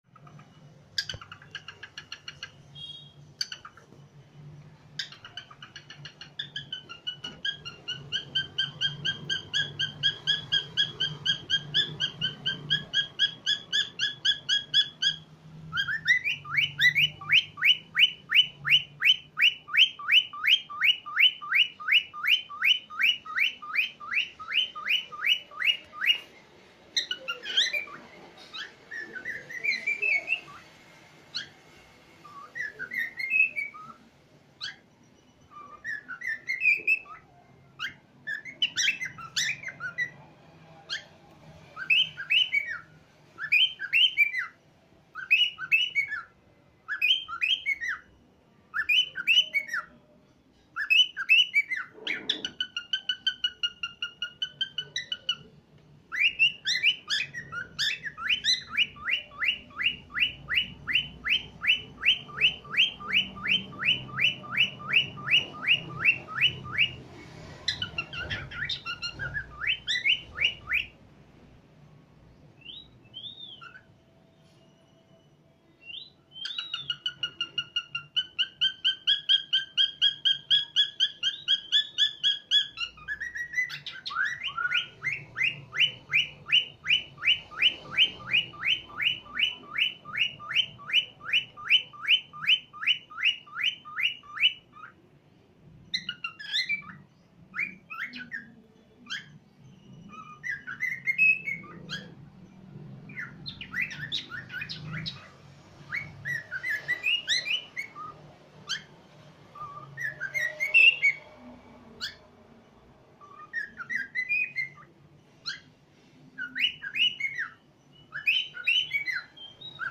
جلوه های صوتی
دانلود صدای عروس هلندی 2 از ساعد نیوز با لینک مستقیم و کیفیت بالا